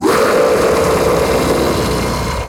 Monster Roar - Geometry Dash High Quality - Bouton d'effet sonore